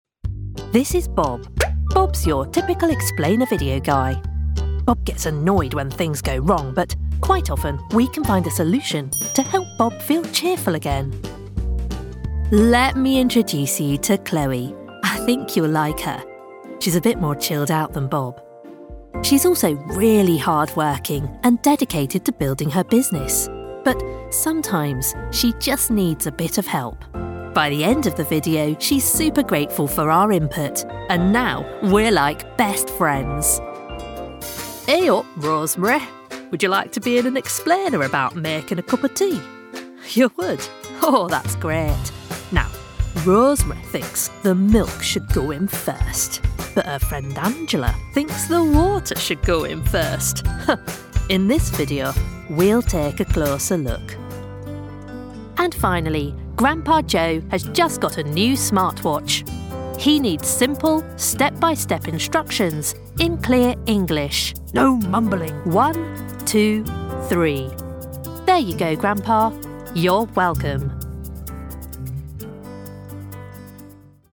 Vídeos explicativos
Mi acento es británico neutro, con tonos tanto de RP como de Londres que pueden ser naturalmente formales o informales según el estilo requerido.
Tengo un estudio de grabación casero especialmente diseñado, lo que significa que puedo producir audio de calidad de transmisión rápidamente y a un precio competitivo.
Micrófono: Rode NT1